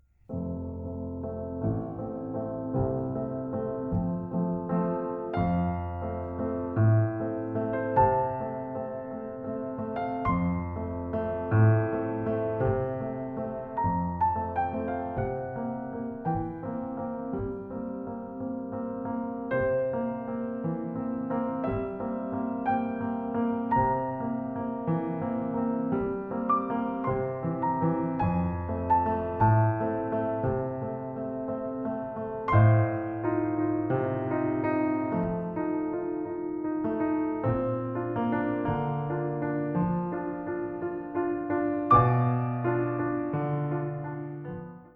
Klavier-Arrangement des Orchesterparts